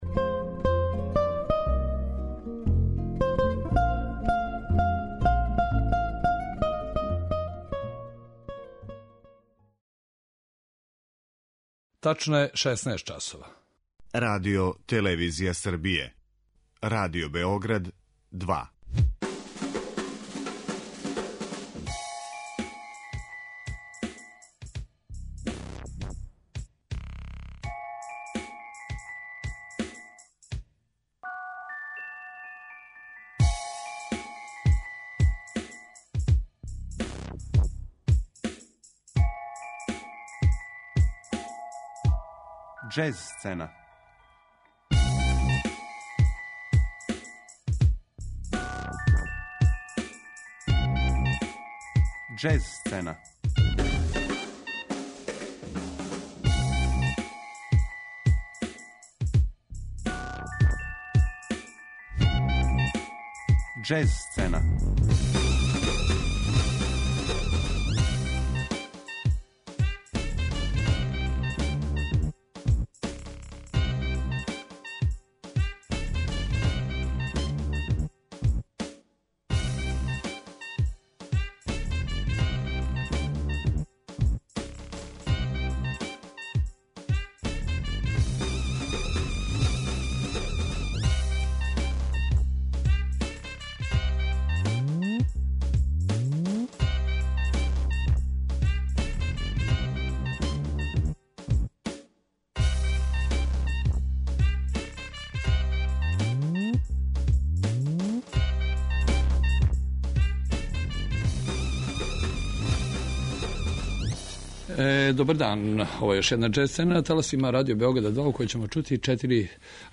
Представљамо четири нова издања са америчке џез сцене.